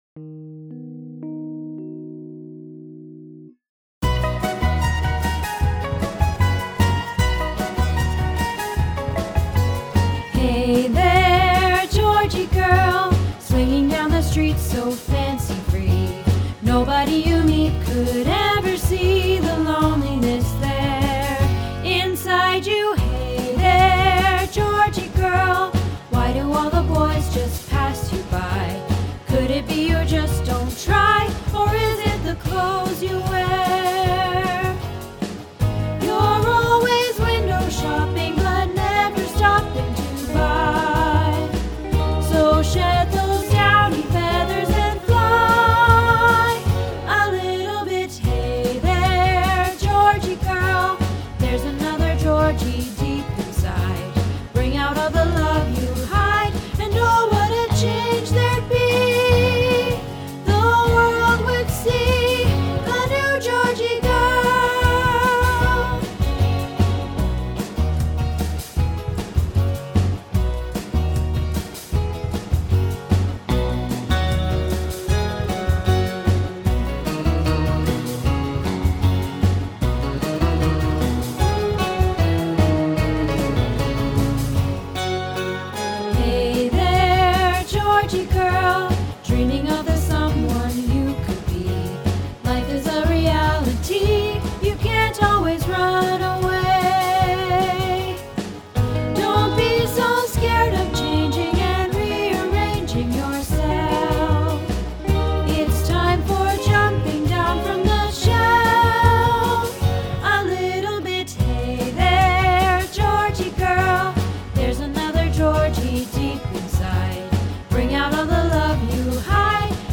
Georgy Girl - Alto